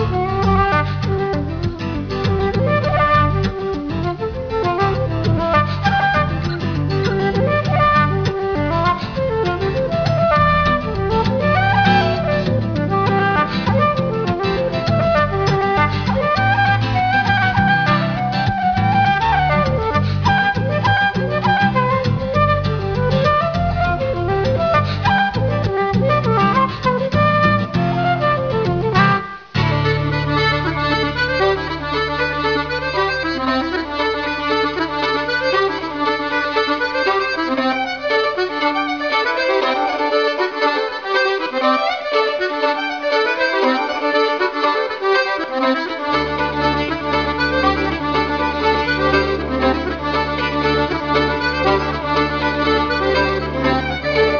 Contemporary/Traditional